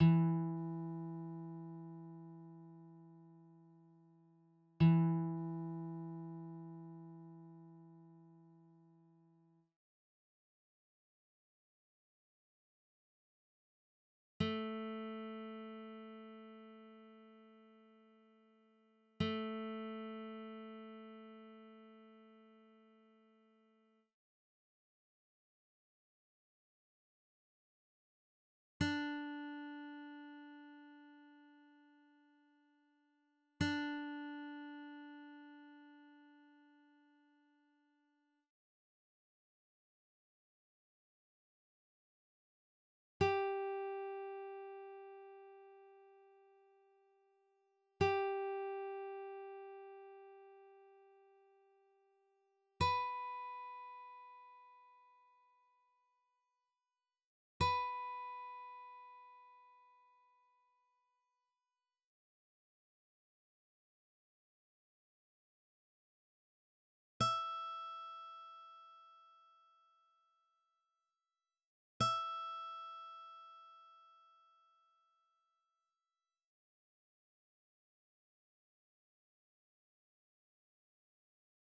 accord des instruments
guitare.wav